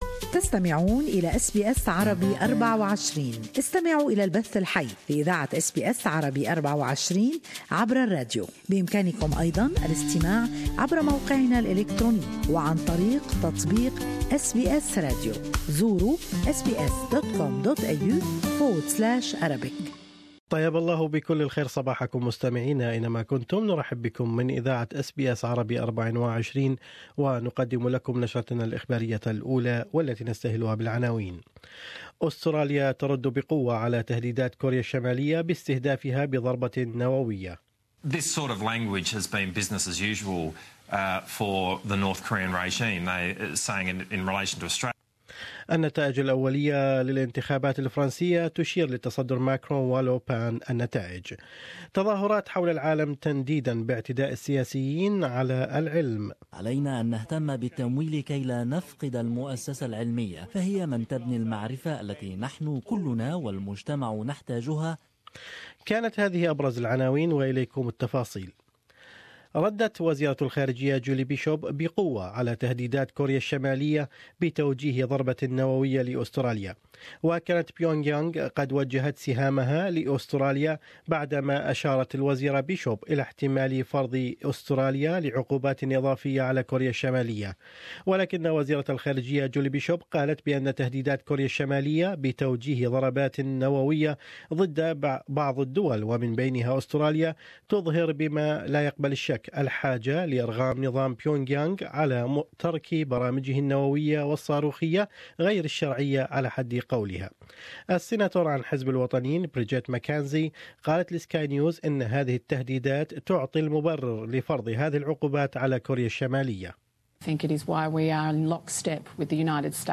In this bulletin ...